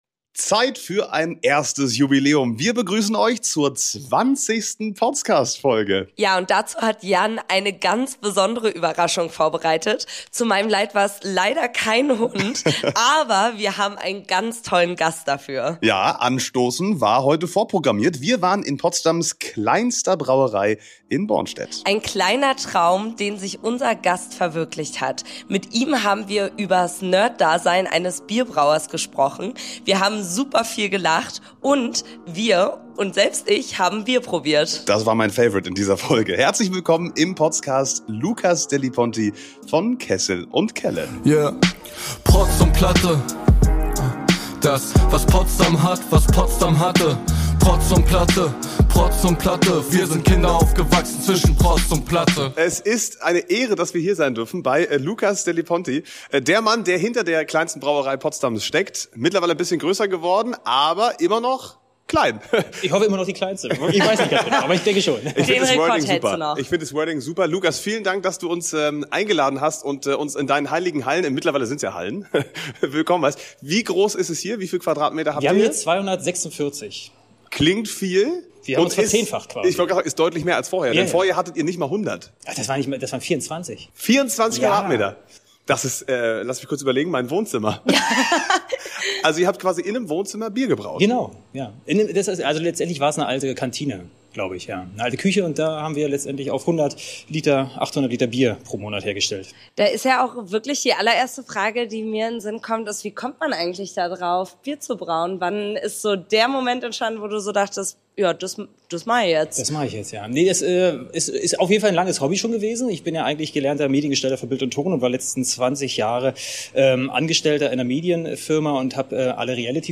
Für unsere 20. Potscast-Folge waren wir bei „Kessel & Kelle“, in Potsdams kleinster Brauerei!